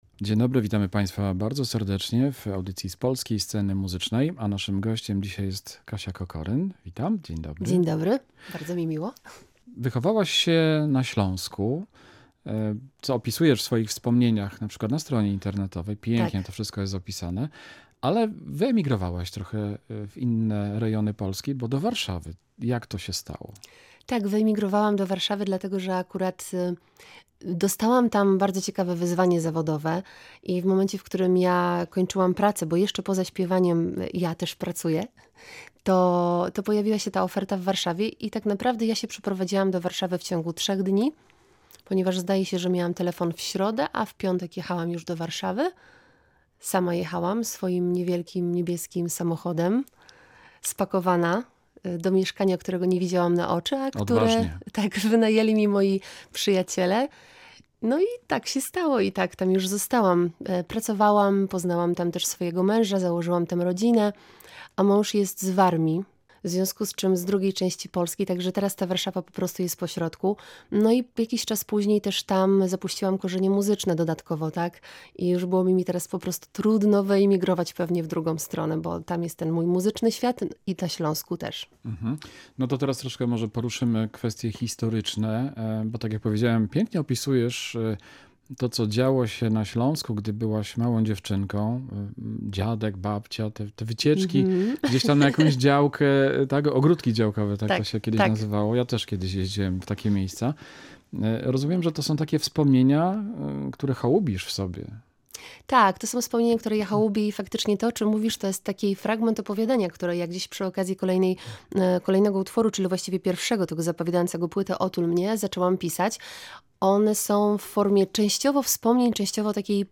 W swoich kompozycjach łączy niezwykłą wrażliwość i emocje z poetyckim sznytem, a czasem cudowna prostotą.
W jej piosence autorskiej słychać wpływy musicalowe oraz brzmienie stylizowane na niezależną muzykę rozrywkową.